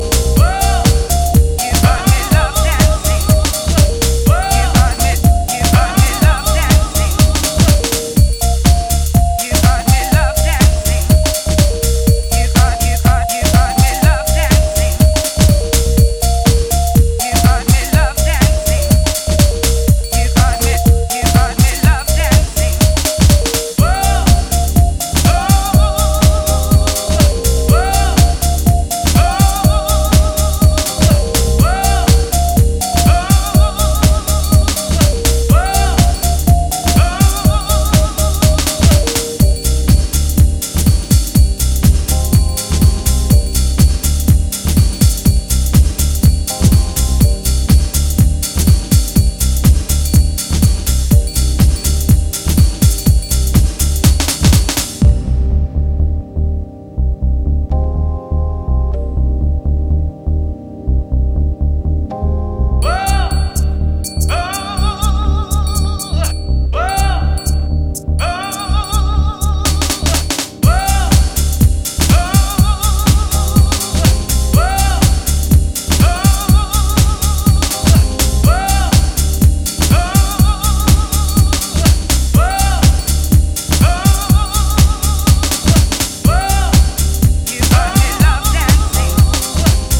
ジャンル(スタイル) HOUSE CLASSIC / DEEP HOUSE